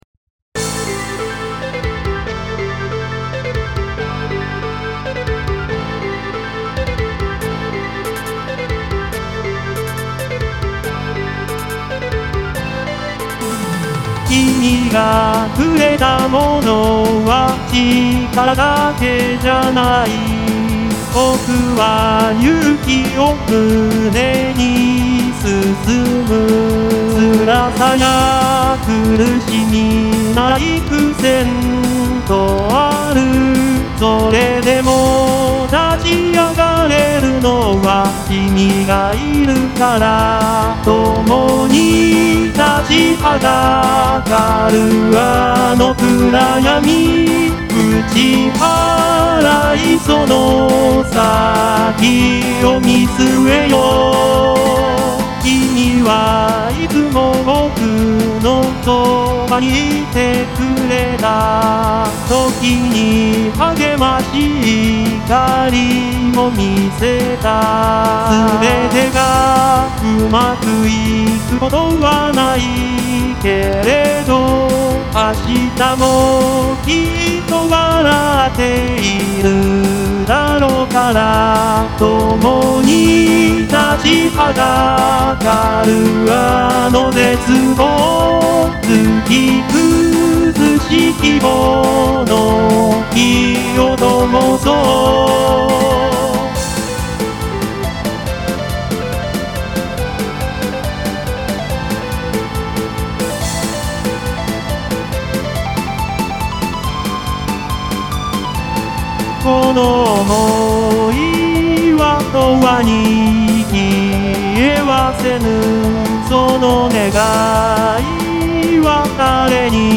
歌もの（ＶＯＣＡＬＯＩＤを使用したもの）
熱い。アニソンみたい、らしい。